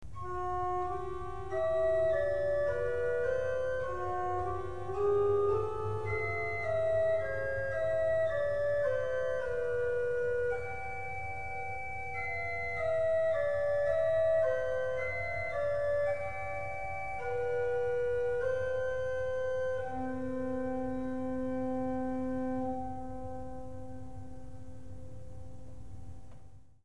Nasard, Nasat
Głos organowy - oznaczenie fletowej kwinty 2 2/3'.
nasard_z_8.mp3